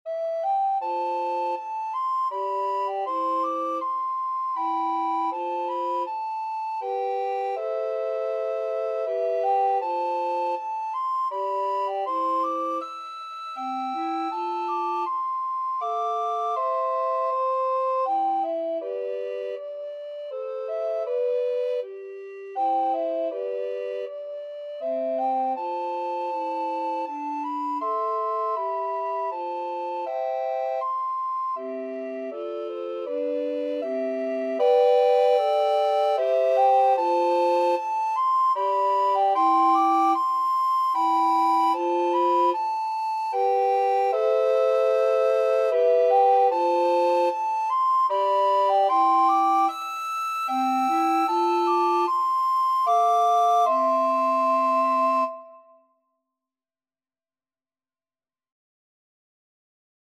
Free Sheet music for Recorder Trio
Alto RecorderTenor Recorder 1Tenor Recorder 2
3/4 (View more 3/4 Music)
Andante
Classical (View more Classical Recorder Trio Music)